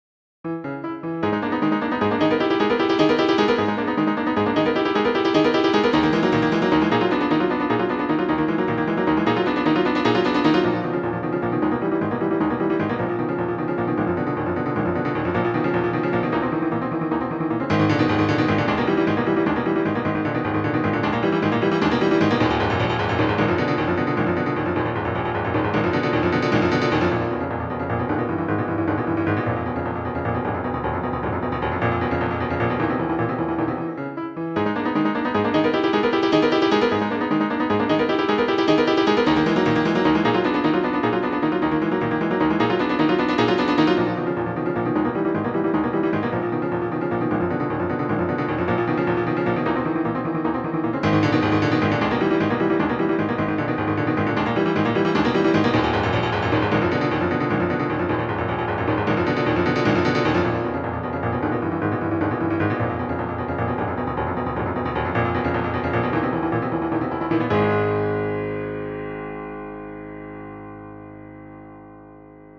Op. 250 - Piano Music, Solo Keyboard - Young Composers Music Forum